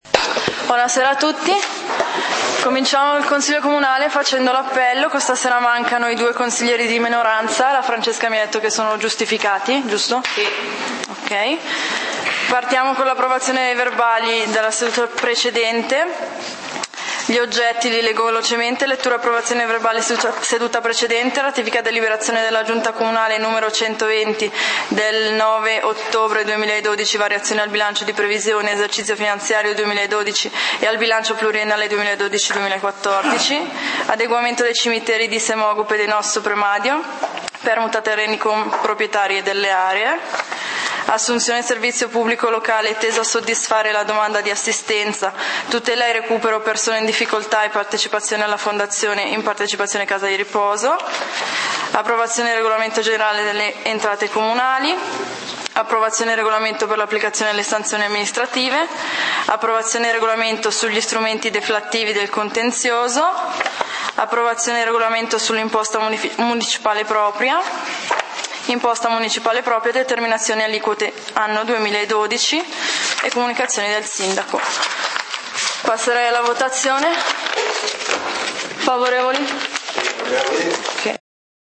Punti del consiglio comunale di Valdidentro del 30 Novembre 2012